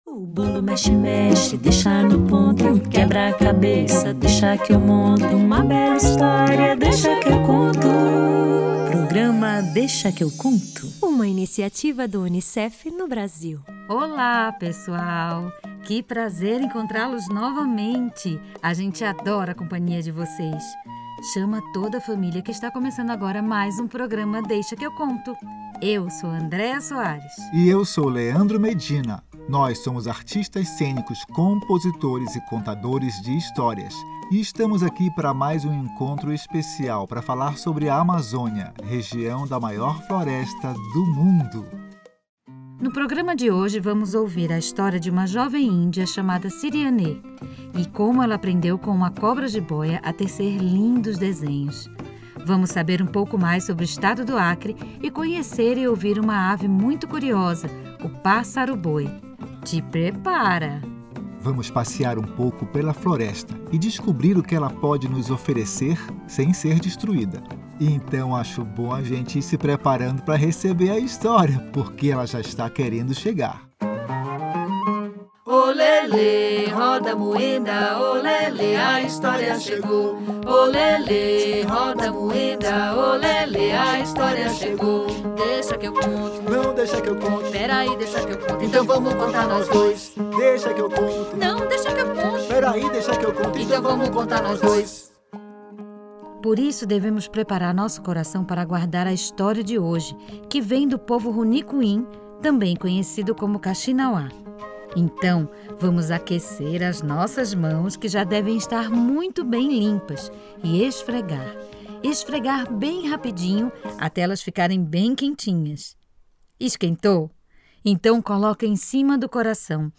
Vamos conhecer um pouco o Acre, um dos estados da região amazônica, e fazer silêncio para ouvir uma ave chamada pássaro-boi. Ainda tem uma brincadeira bem divertida.